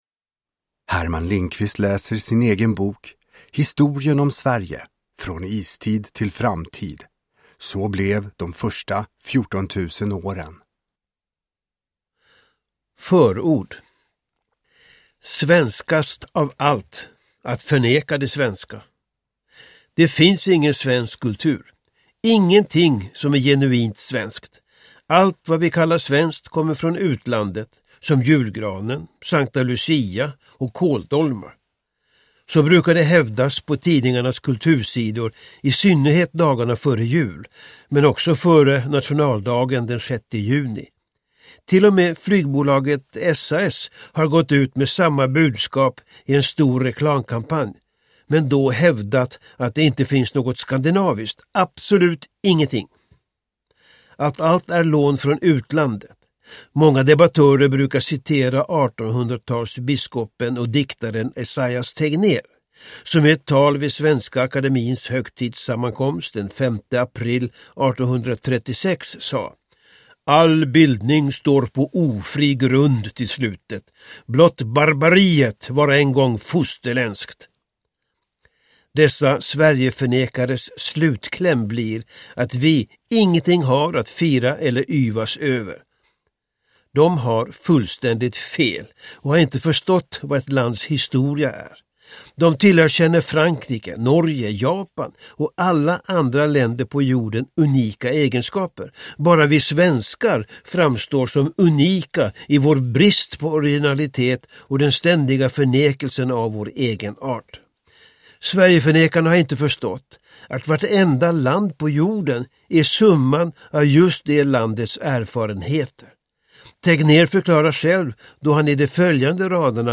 Uppläsare: Herman Lindqvist
Ljudbok